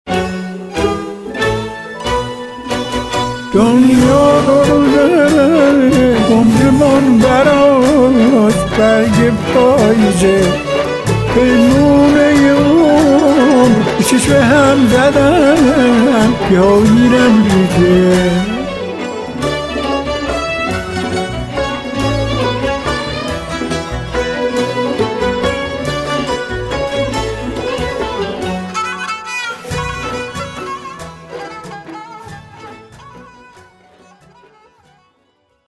ویلن
ویلنسل
پیانو
سنتور
کمانچه
دف و دایره